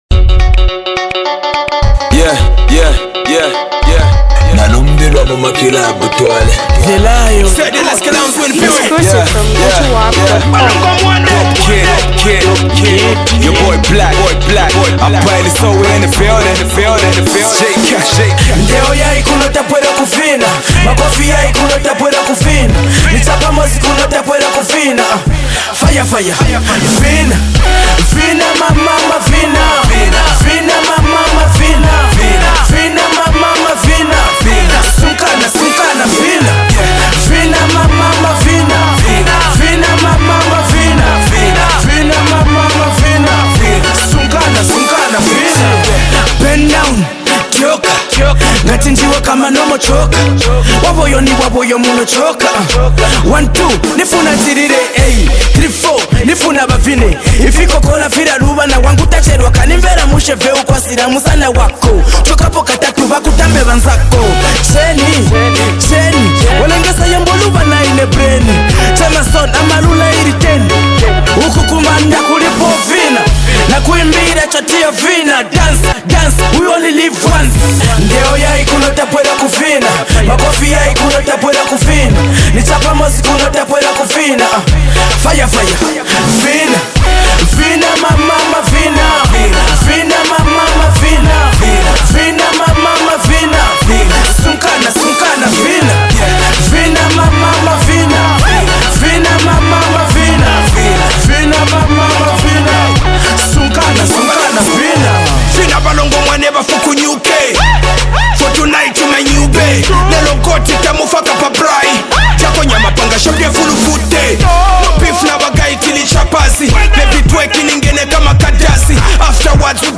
hip-hop
his terrified vocals